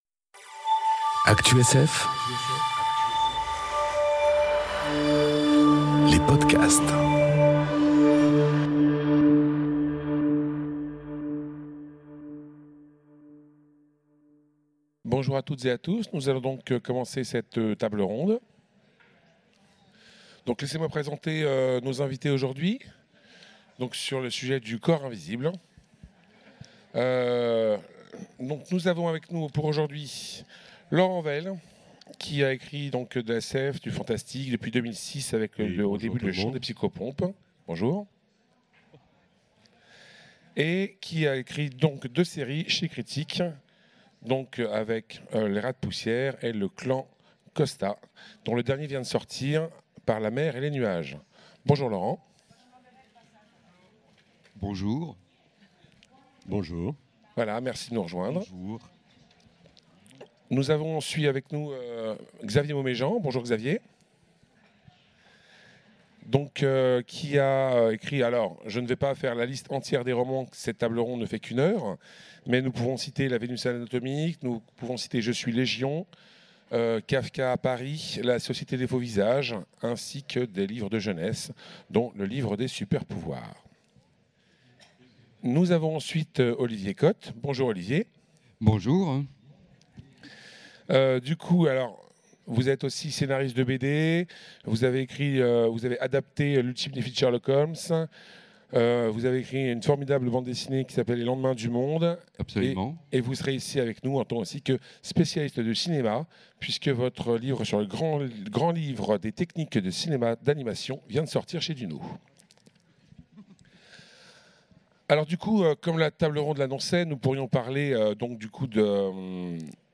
Conférence Le corps invisible enregistrée aux Utopiales 2018